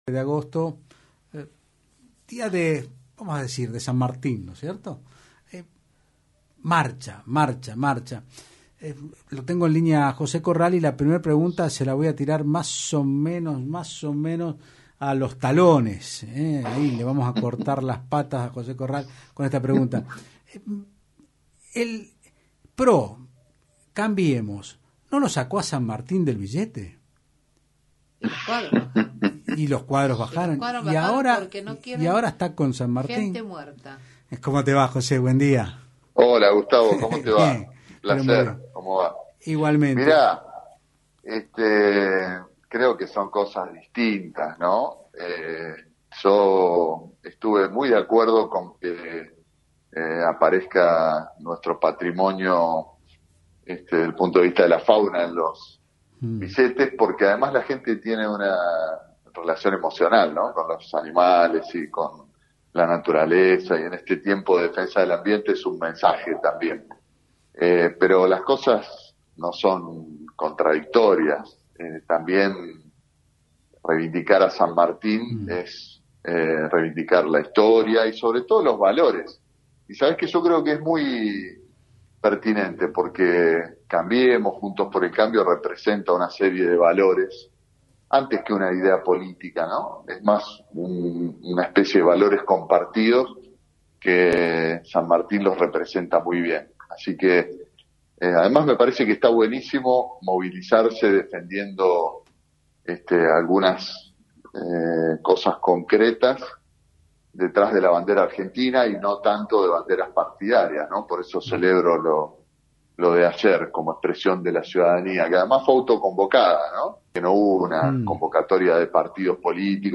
El referente de Juntos por el Cambio José Corral dijo en Otros Ámbitos (Del Plata Rosario 93.5) que el banderazo fue un llamado de atención al Gobierno para que se concentre en lo importante, que es cómo trabajar en la recuperación del país en el marco de la pandemia. También dijo que el gobierno nacional parece más enfocado en ampliar la grieta intentando avasallar las instituciones.